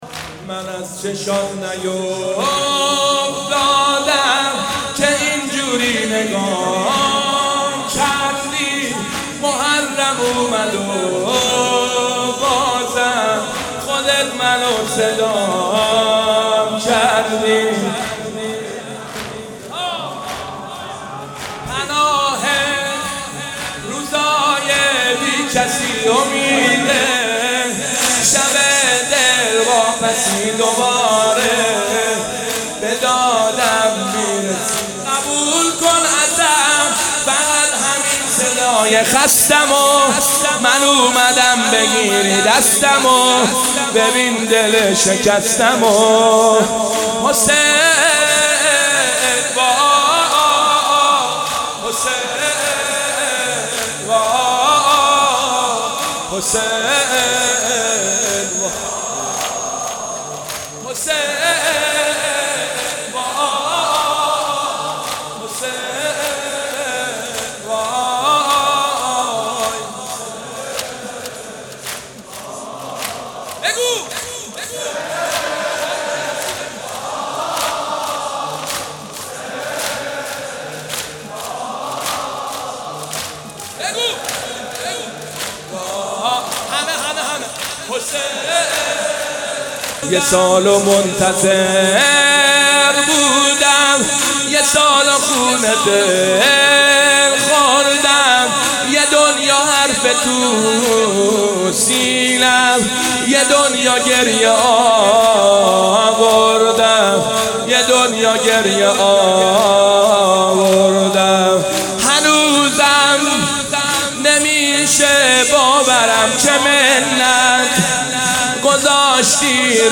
مداحی
شب دوم محرم الحرام در هیئت خادم‌الرضا(ع)